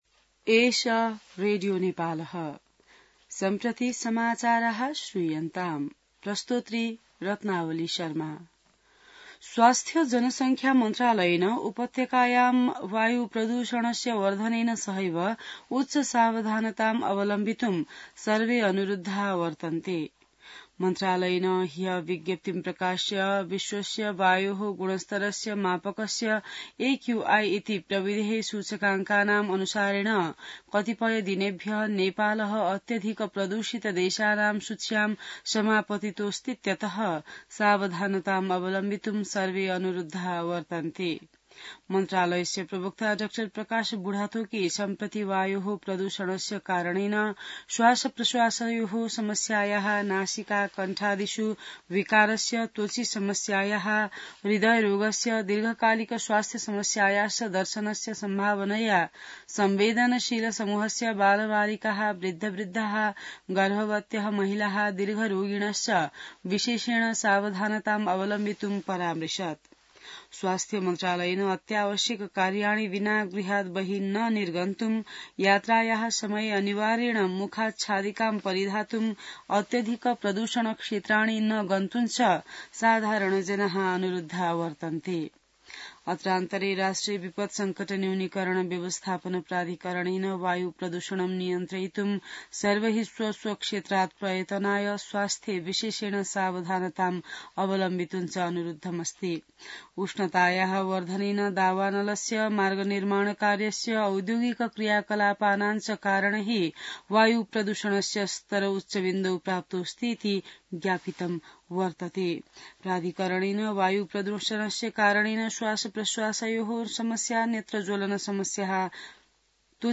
संस्कृत समाचार : २२ चैत , २०८१